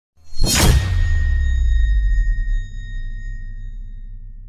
slice sound effect.mp3